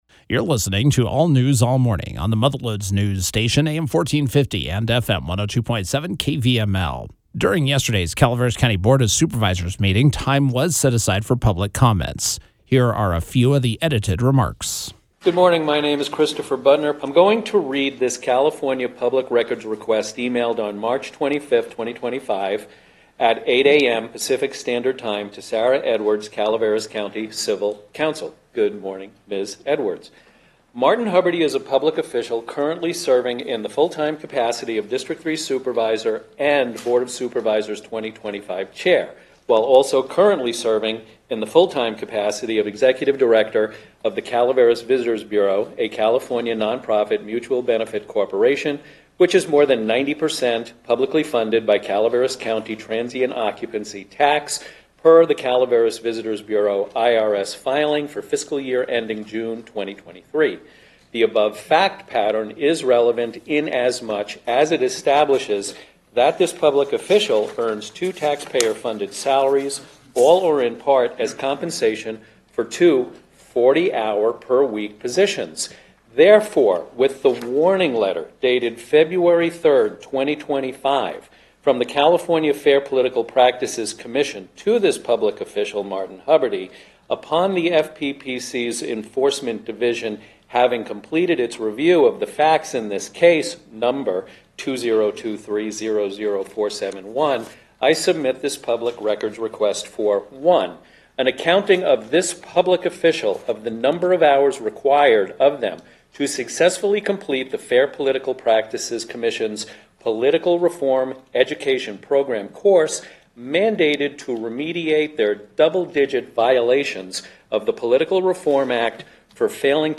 During Tuesday’s Calaveras County Board of Supervisors meeting, time was set aside for public comments.